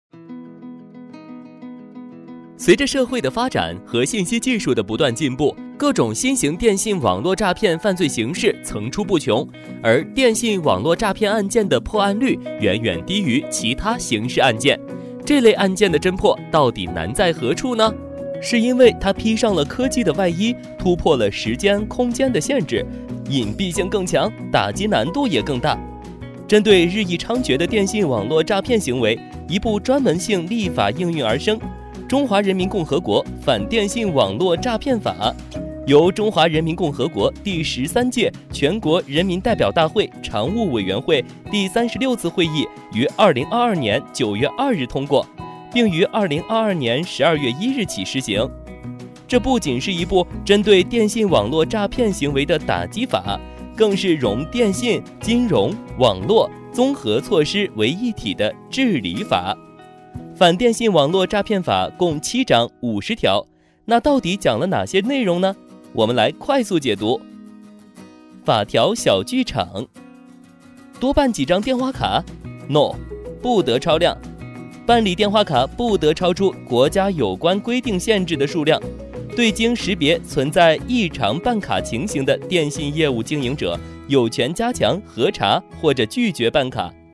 飞碟说-男56-电信诈骗.mp3